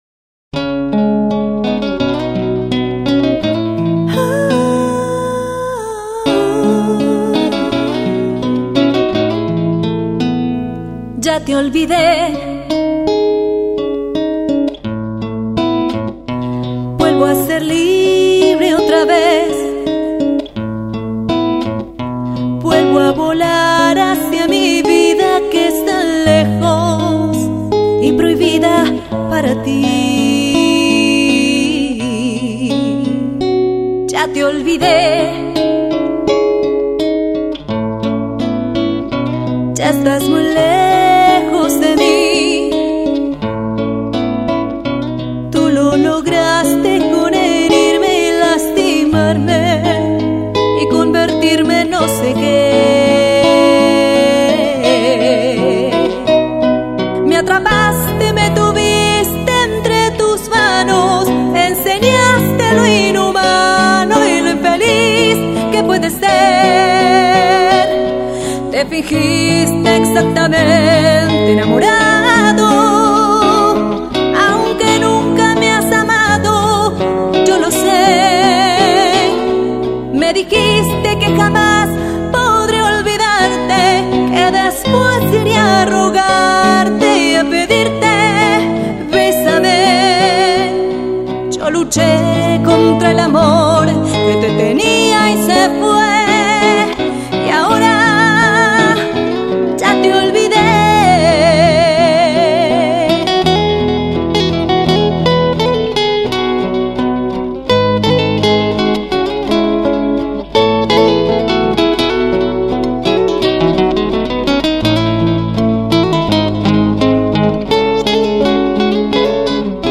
de una manera acústica
guitarra